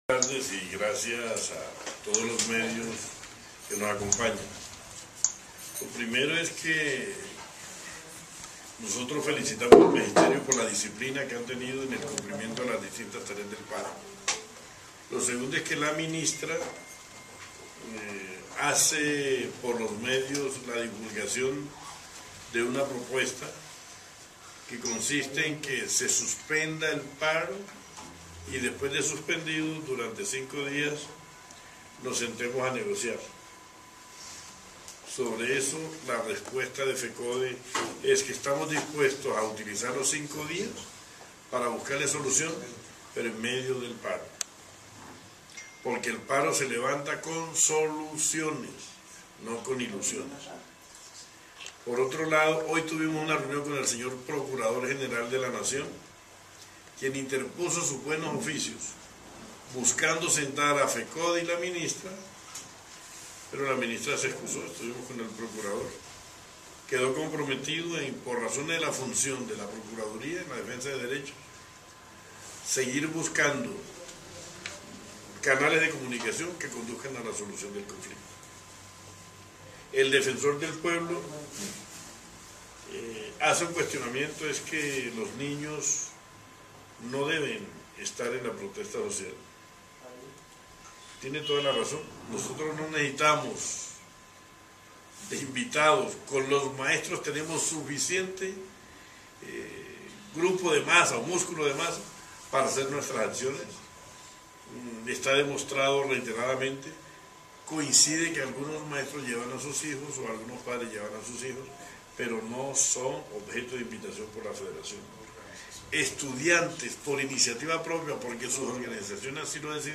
RUEDA DE PRENSA DE FECODE- 28/04/2915